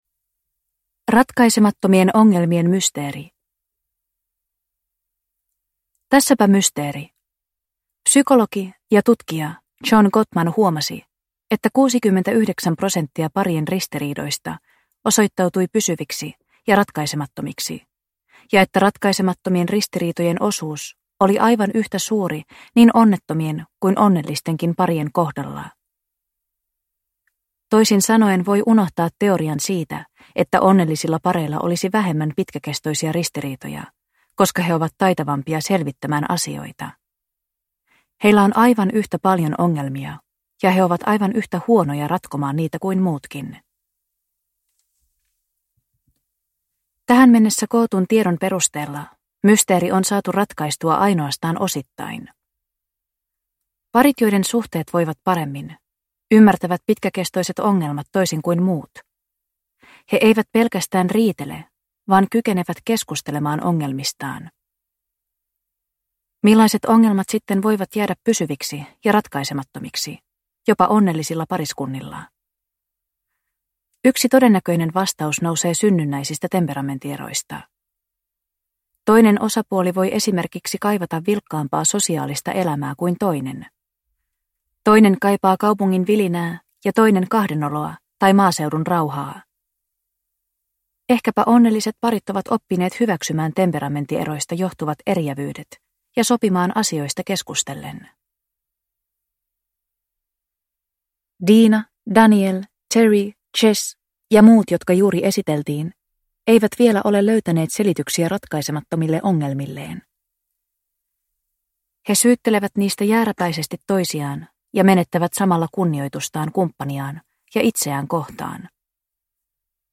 Erityisherkkä ihminen ja parisuhde – Ljudbok – Laddas ner